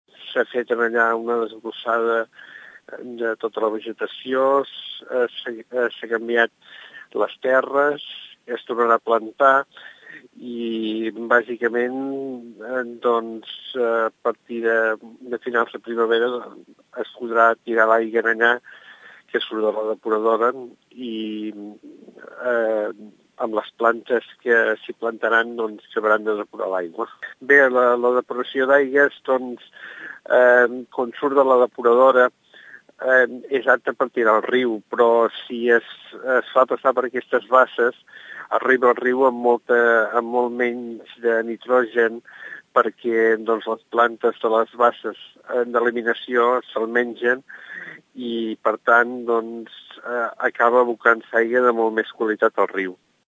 Ens detalla els treballs, el regidor d’obres i serveis, Carles Aulet.